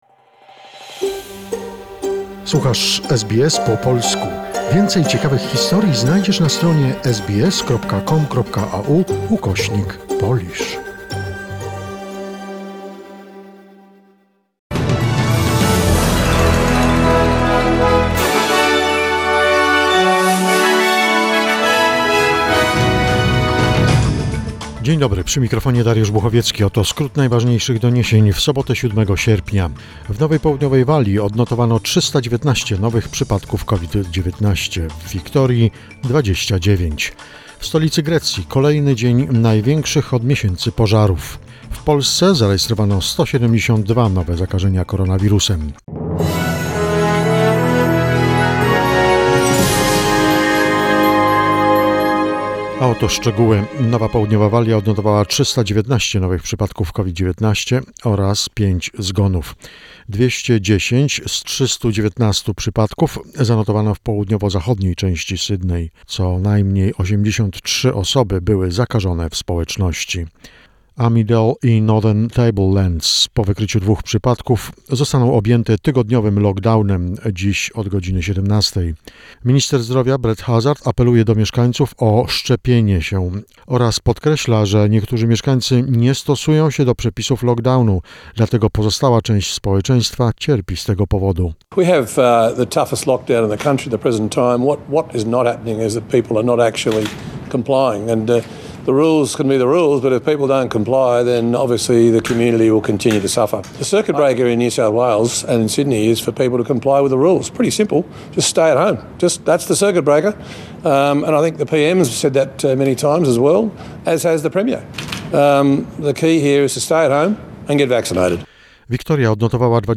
SBS News in Polish, 7 sierpnia 2021